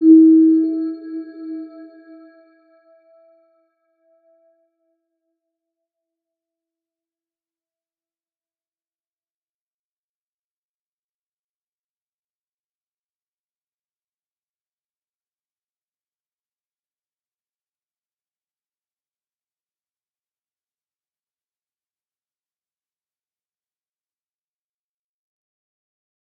Round-Bell-E4-p.wav